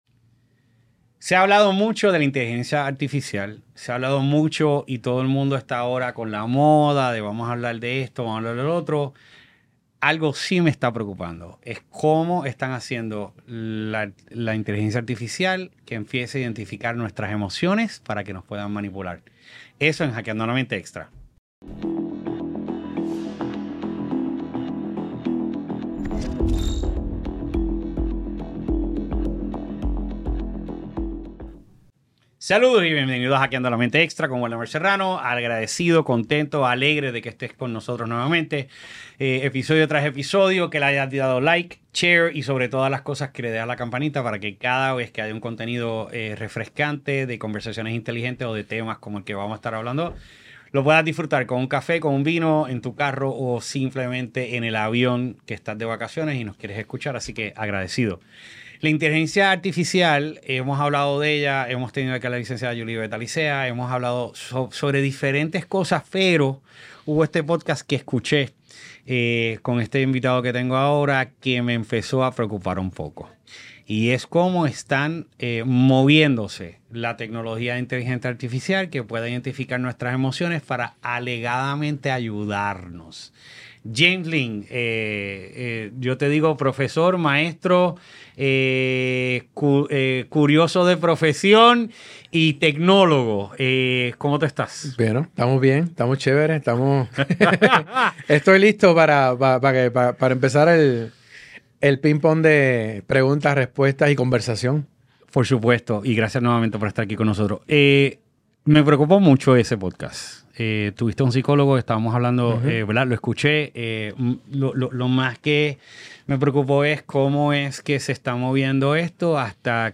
También debatimos los desafíos éticos y las implicaciones de confiar en la IA para interpretar nuestras emociones. ¡No te pierdas esta interesante conversación que podría transformar tu perspectiva sobre la relación entre la tecnología y las emociones humanas!